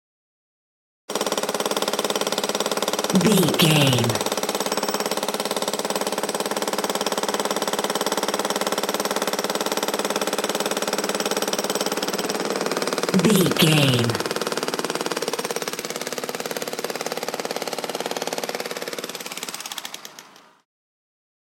Hydraulic hammer
Sound Effects
urban
hard